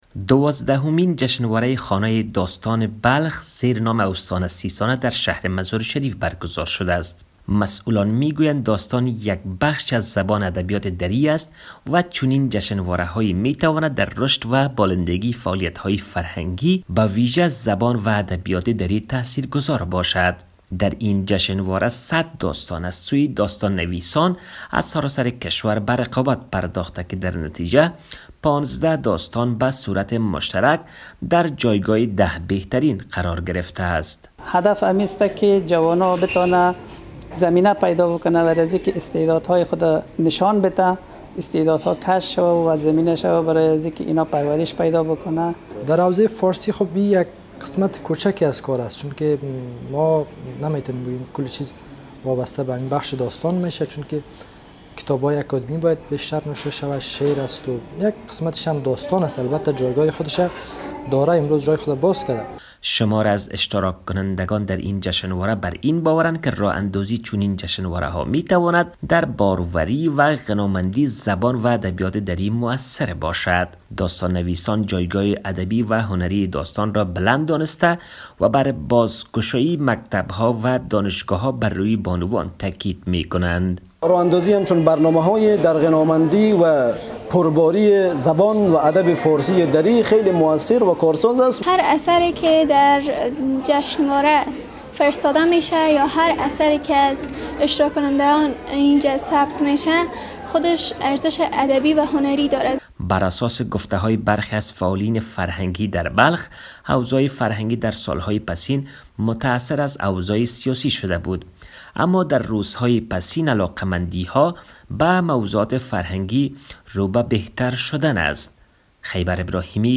خبر / فرهنگی